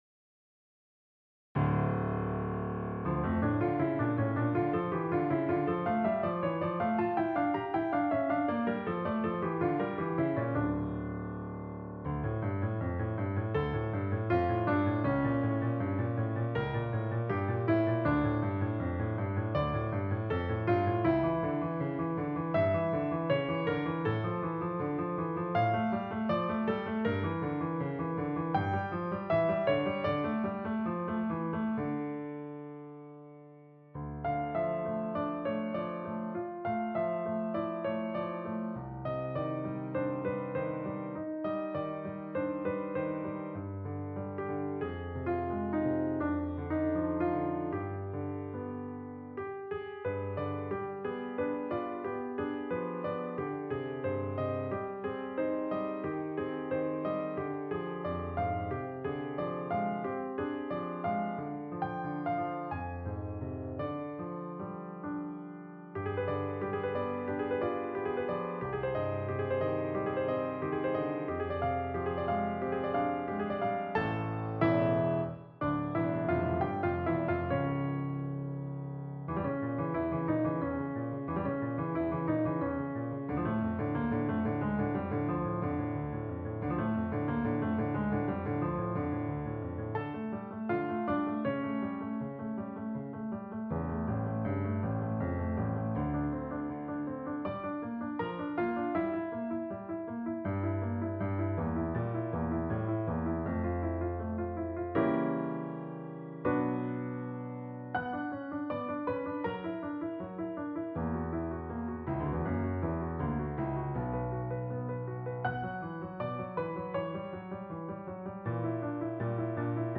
Tableau No. 5 - Piano Music, Solo Keyboard